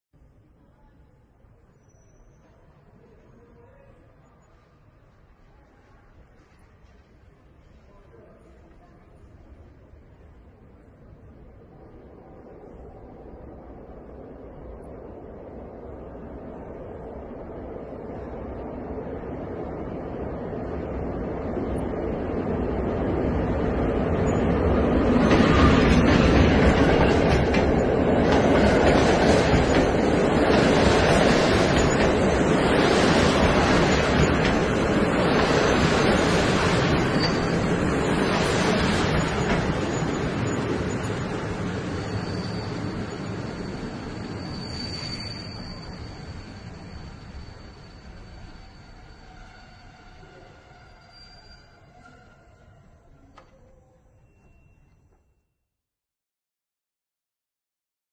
描述：在当地荷兰铁路公司从Schiedam Centrum到鹿特丹中央车站的列车上记录了自动公告。
Tag: 本地 鹿特丹 荷兰 列车 铁路 自动化的 中央 终点 公告